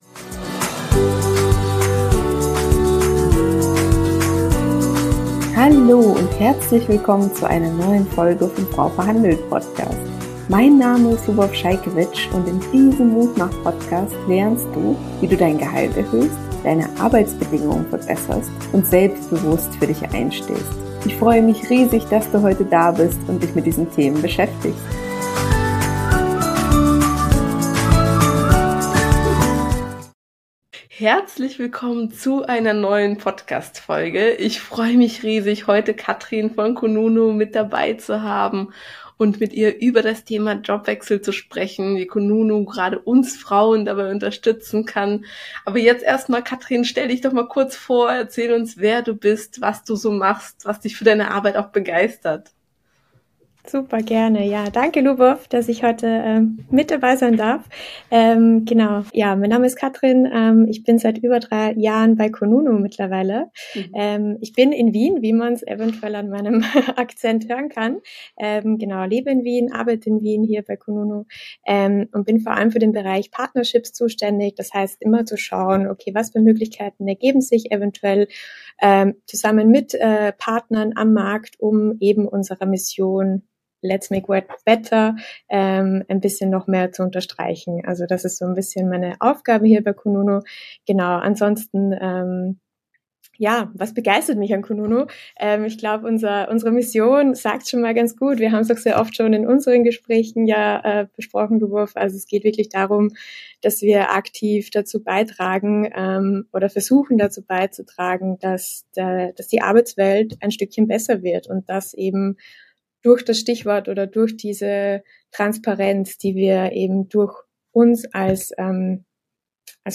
#90 Die besten Arbeitgeber für Frauen - Interview mit kununu ~ FRAU VERHANDELT - Gehaltsverhandlung für Frauen Podcast